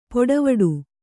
♪ poḍavaḍu